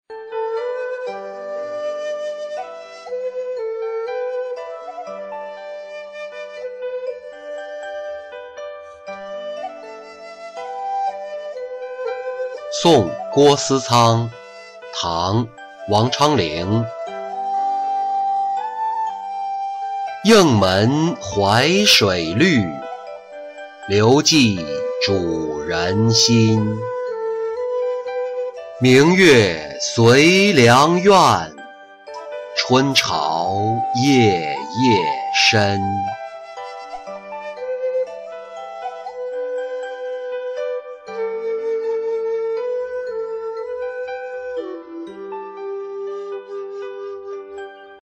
送郭司仓-音频朗读